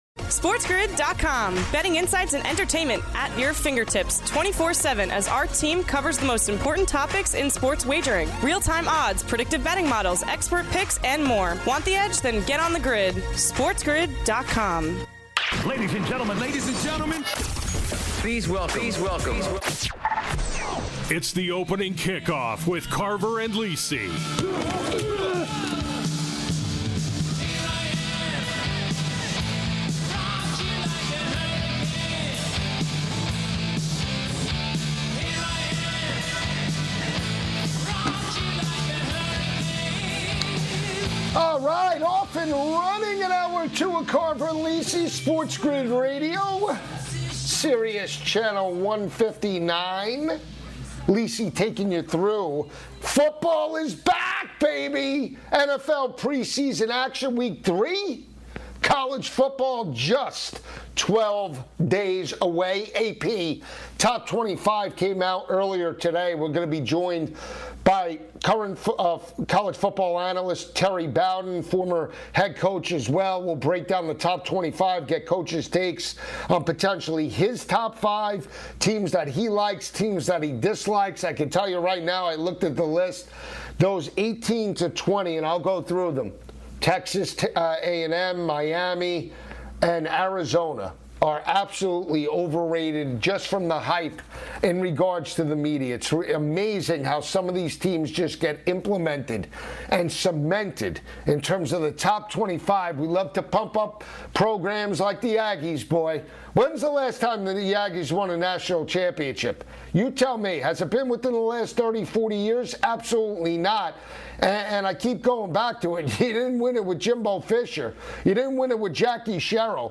Plus, Coach Terry Bowden joins to discuss the latest in college football!